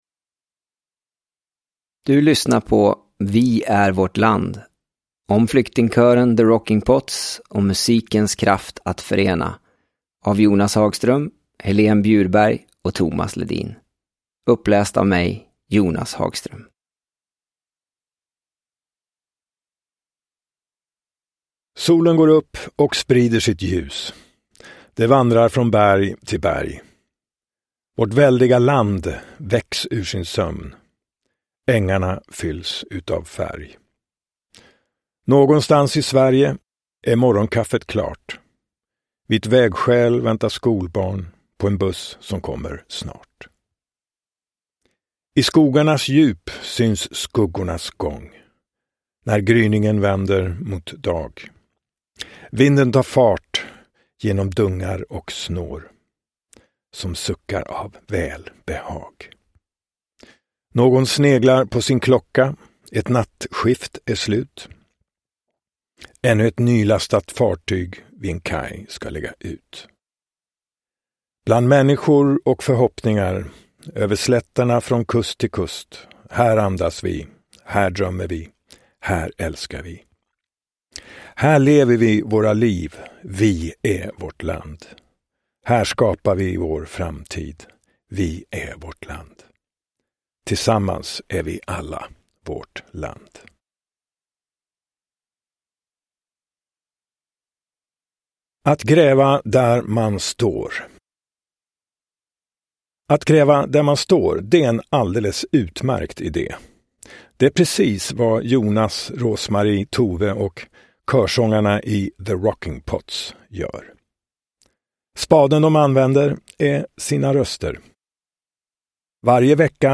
Vi är vårt land : om flyktingkören The Rockin’ Pots och musikens kraft att förena – Ljudbok – Laddas ner